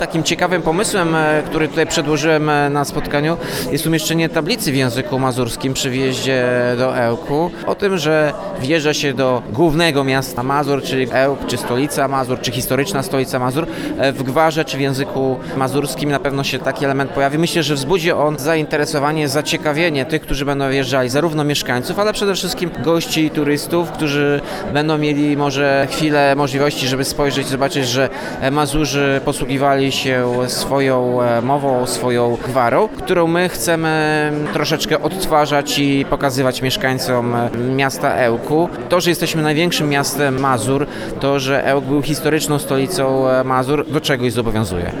Rosnące zainteresowanie mową mazurską nie umknęło uwadze ełckiego samorządu. Obecny na spotkaniu autorskim prezydent, Tomasz Andrukiewicz zauważył, że elementarz może być wykorzystywany, jako pomoc naukowa w miejscowych szkołach, ale nie tylko.